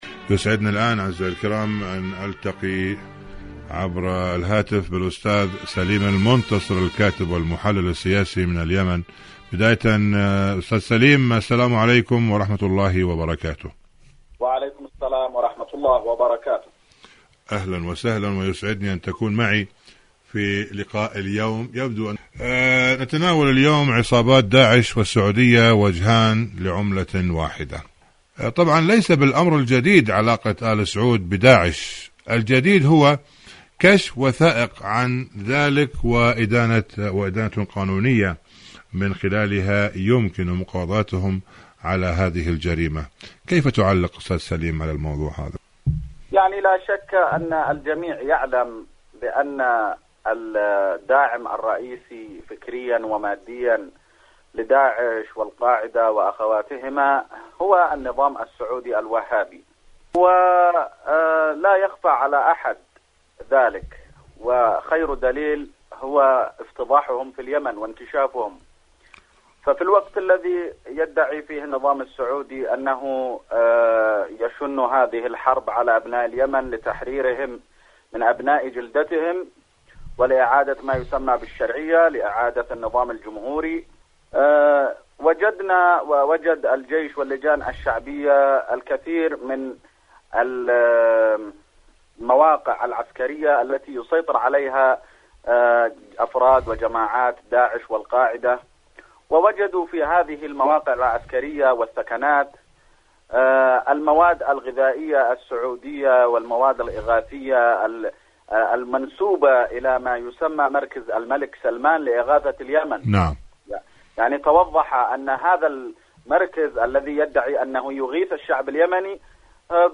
مقابلات إذاعية عصابات داعش السعودية وجهان لعملة واحدة